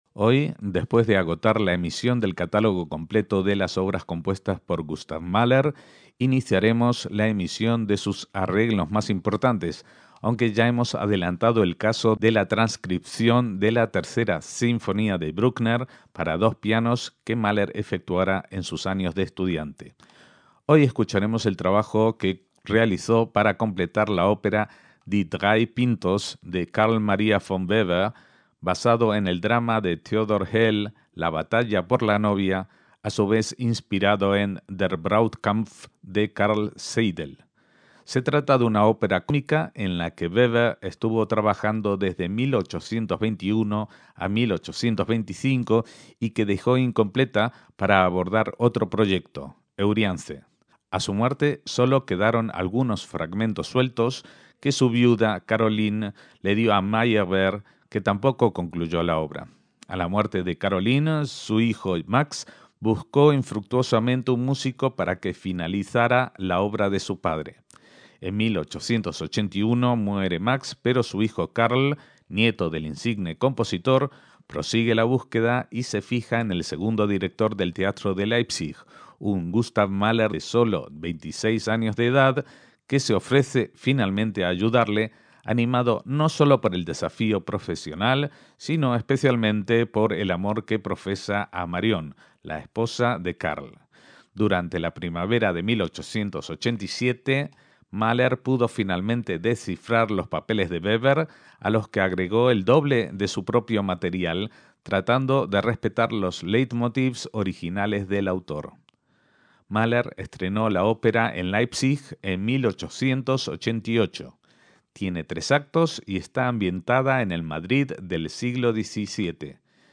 ópera cómica